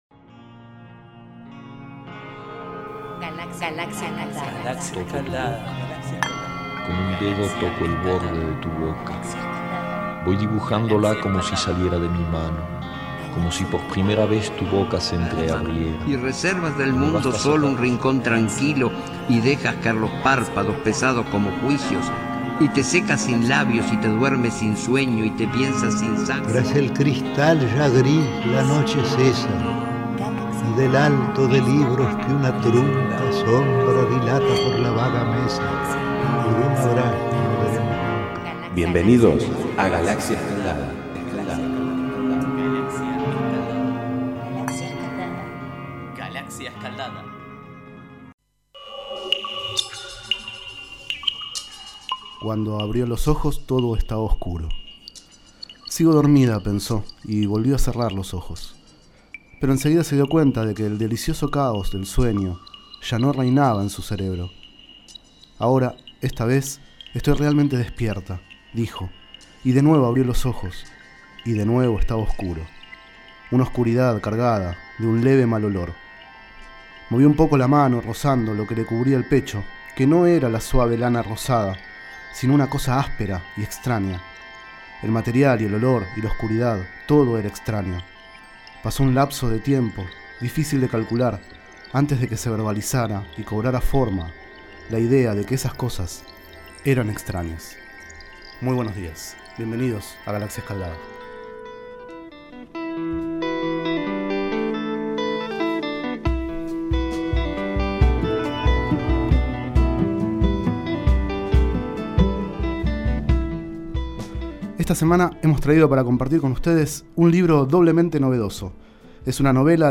38º micro radial, realizado el 1º de diciembre de 2012, sobre el libro El diván victoriano, de Marghanita Laski.
Este es el 38º micro radial, emitido en los programas Enredados, de la Red de Cultura de Boedo, y En Ayunas, el mañanero de Boedo, por FMBoedo, realizado el 1º de diciembre de 2012, sobre el libro El diván victoriano, de Marghanita Laski.